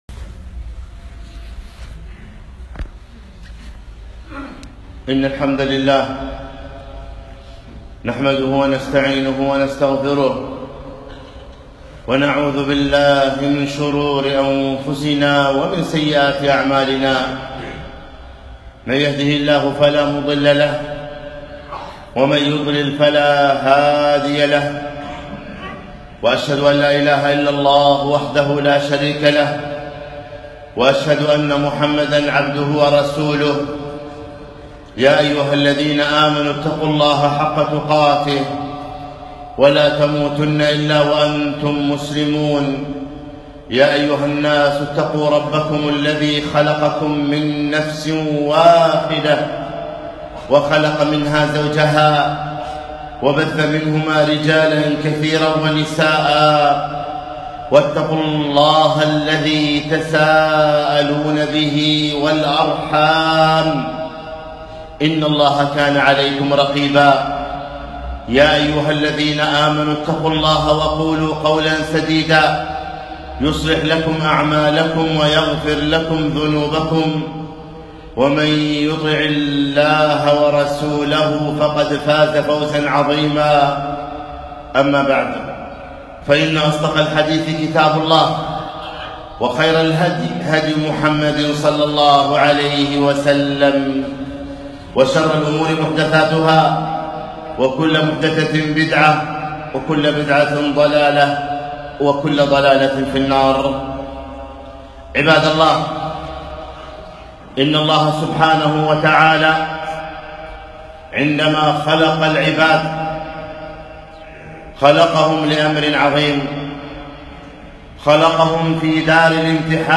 خطبة - البلاء في السراء والضراء